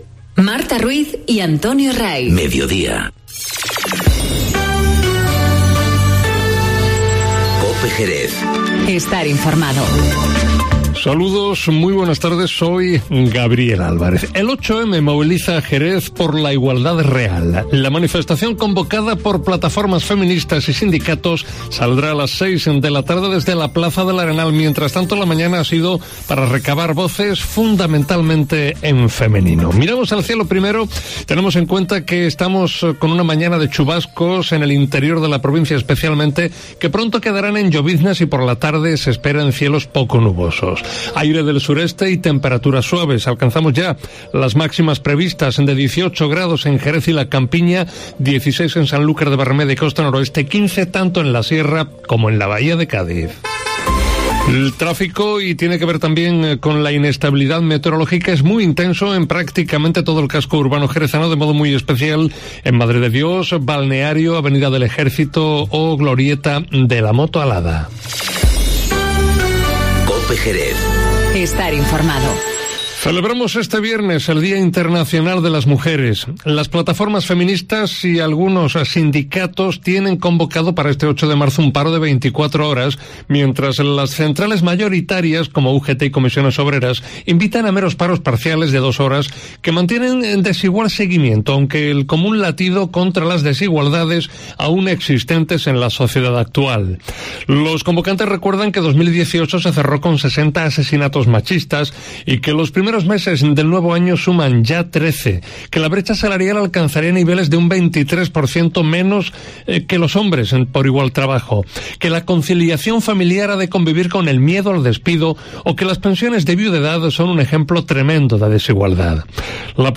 Informativo Mediodía COPE Jerez